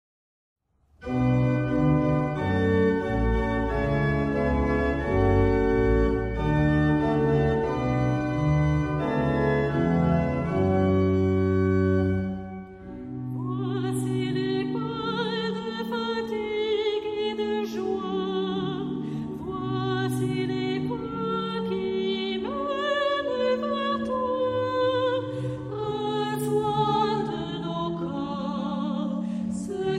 Tonalité : sol mineur